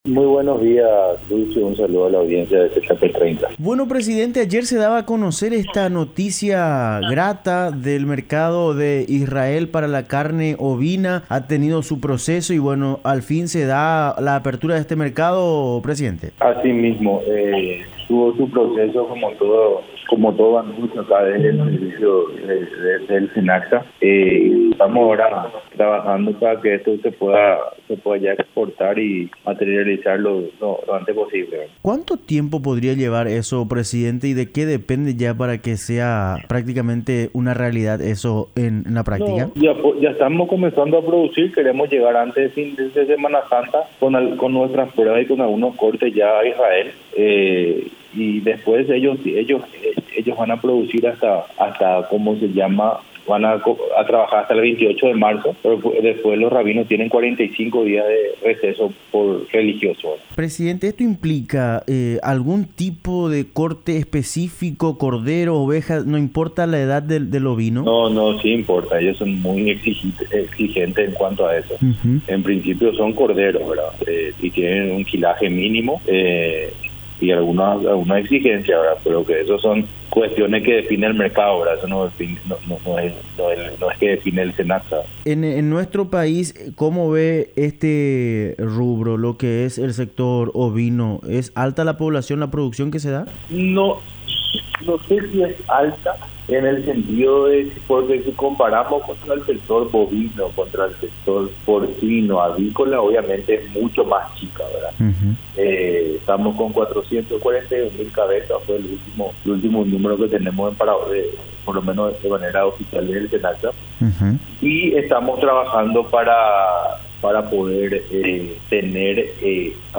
Entrevistas / Matinal 610
Entrevistado: José Carlos Martin Campercholi
Estudio Central, Filadelfia, Dep. Boquerón